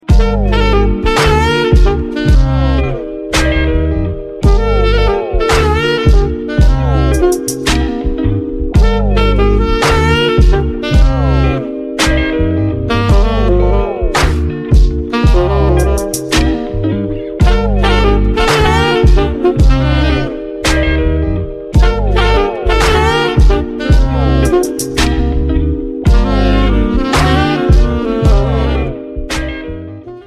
RnB & Garage
Instrumental Saxophone